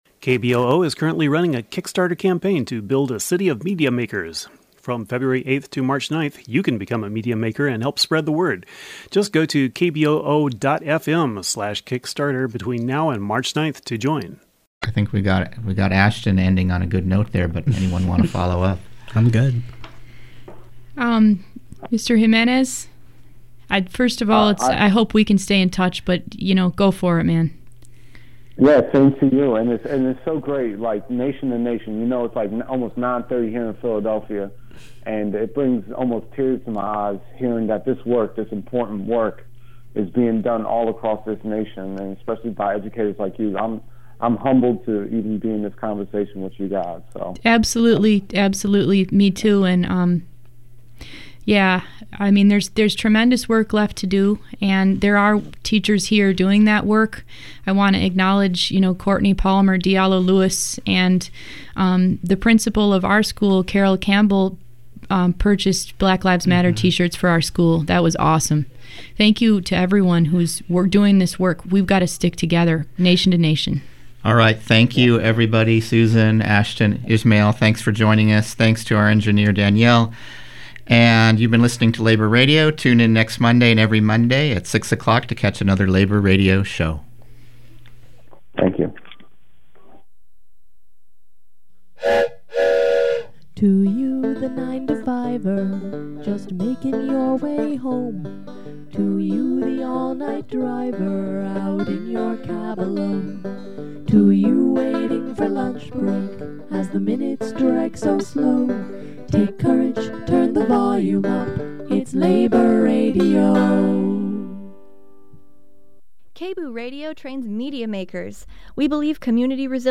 Meditation at Coffee Creek Correctional Facility: Interview